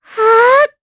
Luna-Vox_Casting2_jp.wav